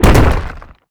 rock_impact_spike_trap_02.wav